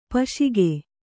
♪ paśige